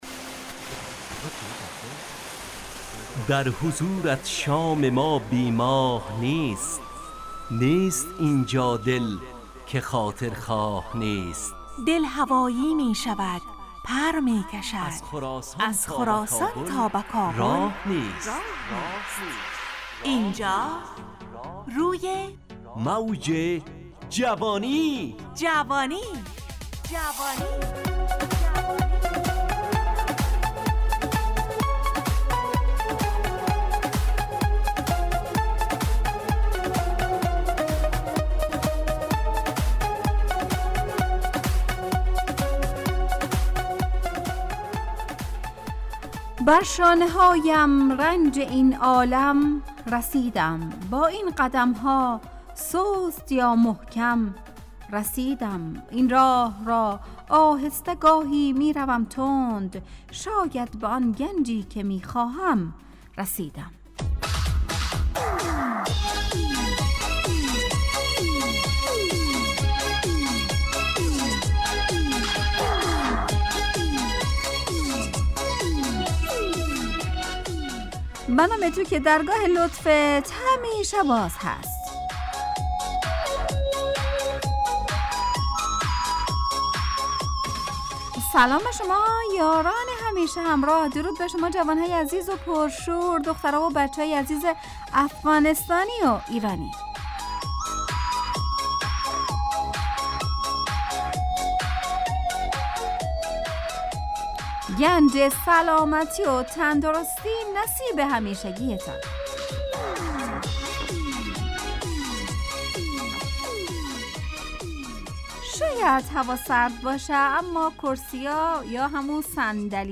همراه با ترانه و موسیقی مدت برنامه 55 دقیقه . بحث محوری این هفته (رنج و گنج) تهیه کننده